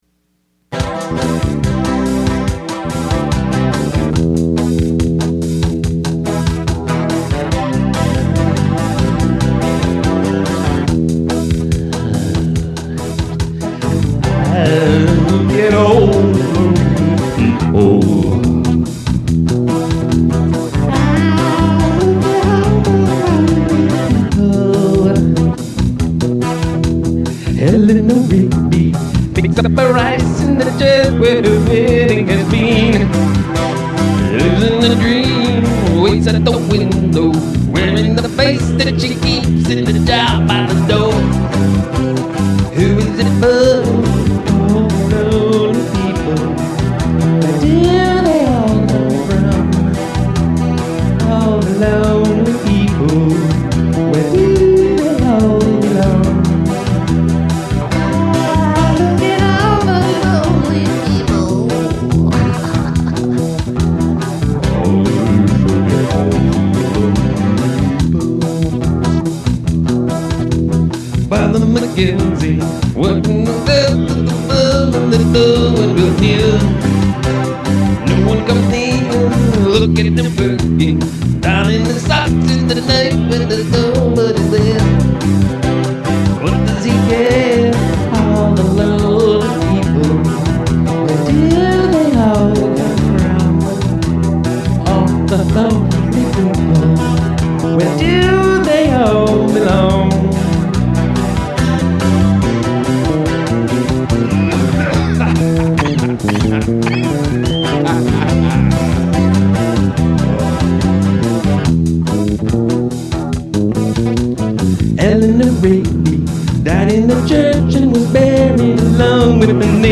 a nice Guild acoustic/electric
Bass track. One that could take the solo and push the song.